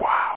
Amiga 8-bit Sampled Voice
1 channel
wow.mp3